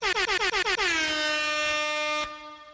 6YES AIR HORN
Category: Sound FX   Right: Personal